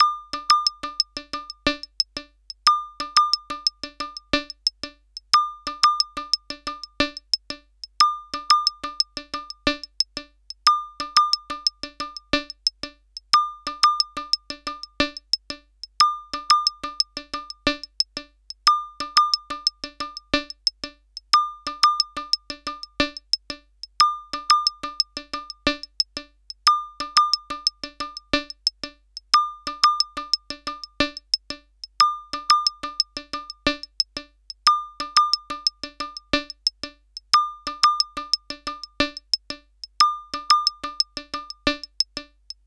Bucle de percusión electrónica
Música electrónica
repetitivo
sintetizador